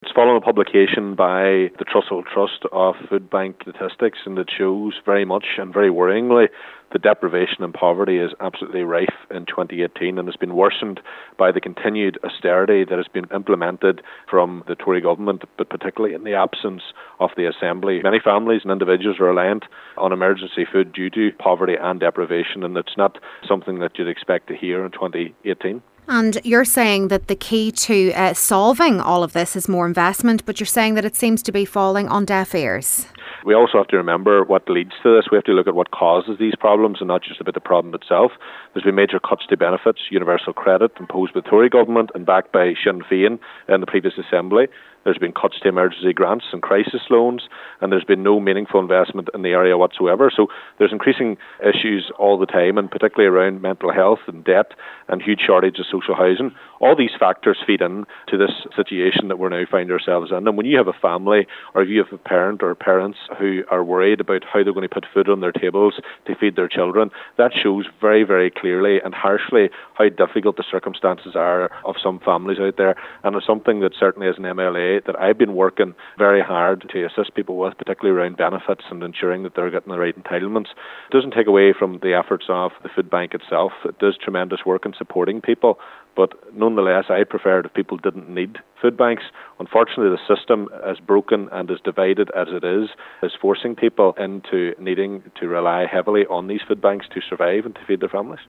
West Tyrone MLA Daniel McCrossan says the issue runs much deeper, owing to major cuts to benefits, emergency grants and crisis loans over the years